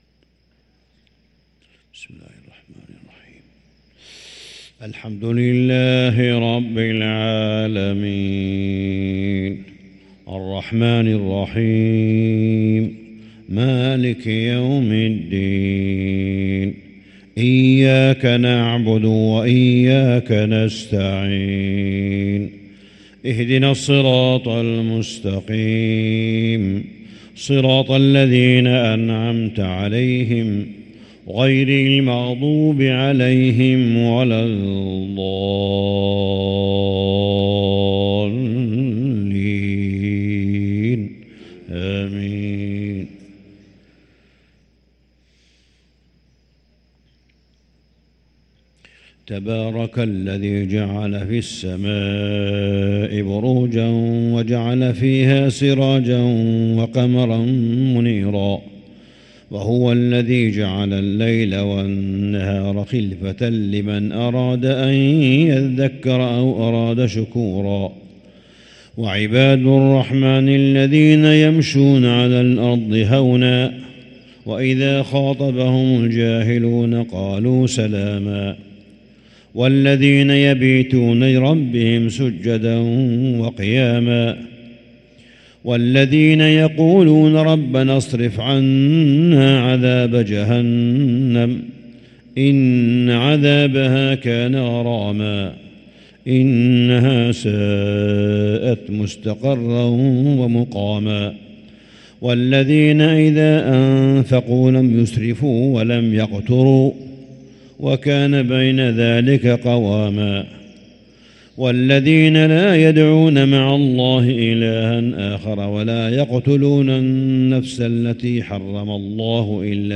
صلاة الفجر للقارئ صالح بن حميد 26 رمضان 1444 هـ